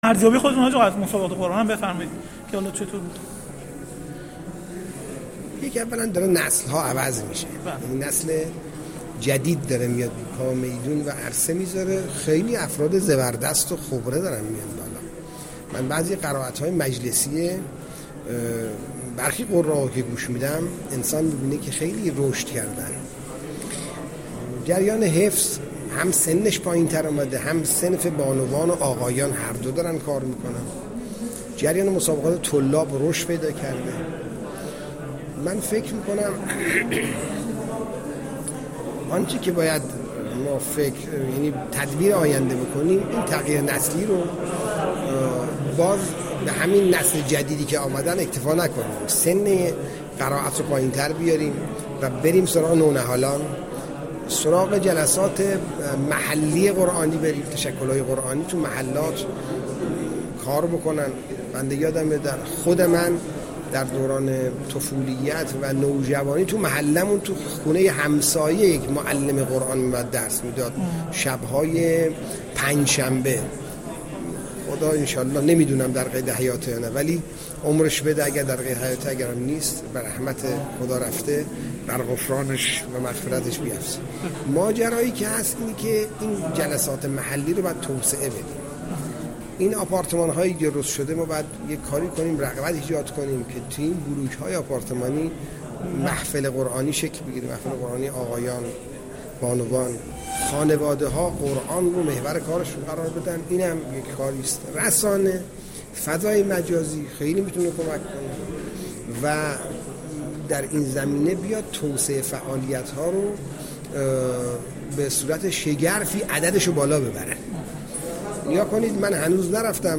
حجت‌الاسلام والمسلمین سیدمهدی خاموشی، نماینده ولی‌فقیه و رئیس سازمان اوقاف و امور خیریه در گفت‌وگو با ایکنا در پاسخ به سؤالی مبنی بر ارزیابی وی از سی و هشتمین دوره مسابقات بین‌المللی قرآن جمهوری اسلامی ایران و برنامه سازمان اوقاف برای تقویت فعالیت‌های قرآنی در این عرصه گفت: نسل‌ها در حال تغییر شدیدی هستند و امروز نسلی در عرصه هنر قرائت فعال شده که بسیار زبردست و خبره است.